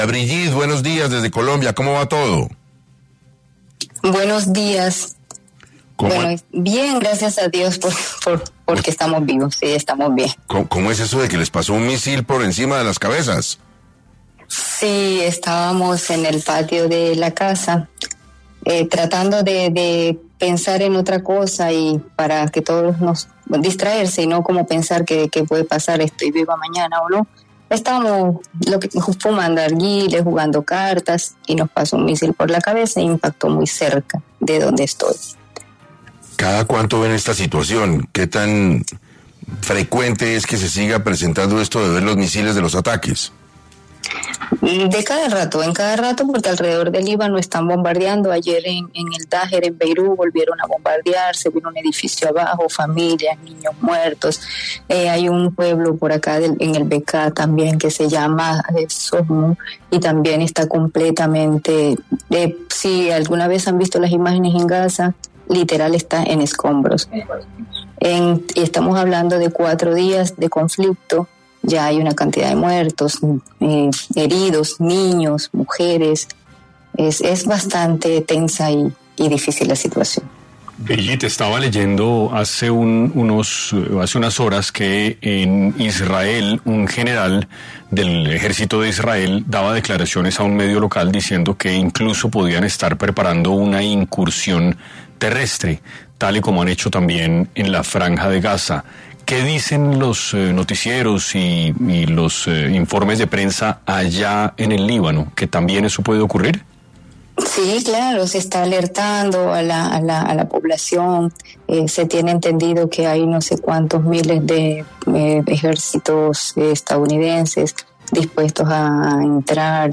En 6AM de Caracol Radio, se conectó